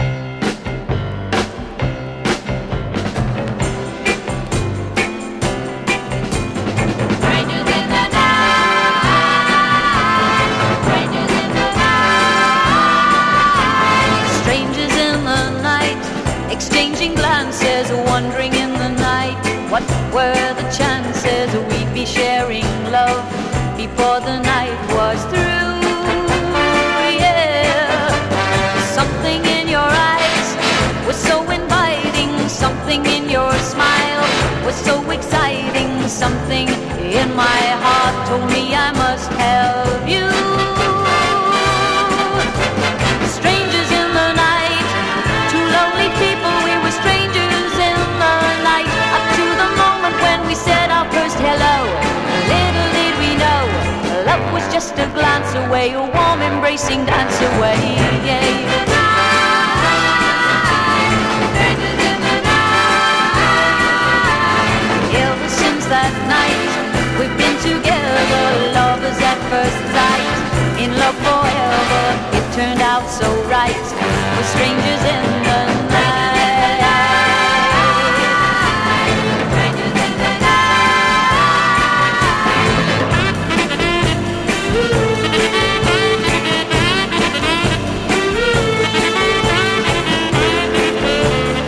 多幸感に溢れ眩しいくらいに輝くガールポップ・ソウル